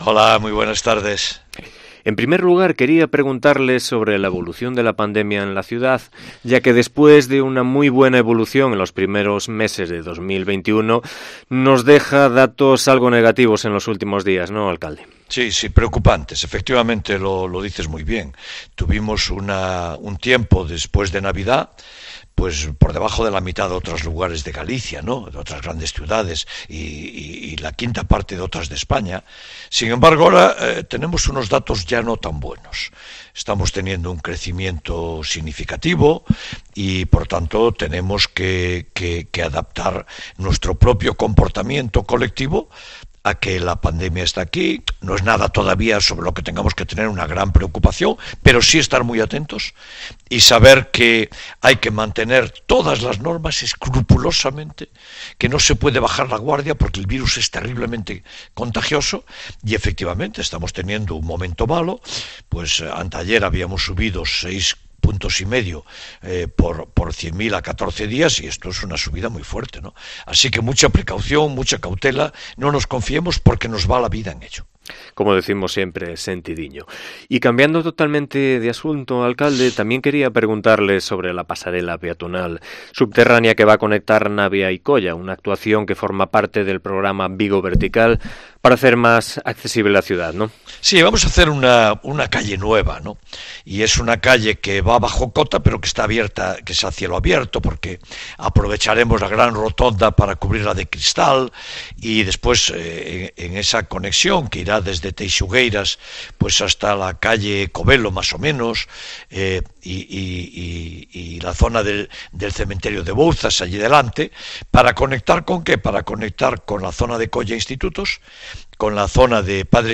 AUDIO: Entrevista a Abel Caballero, alcalde de Vigo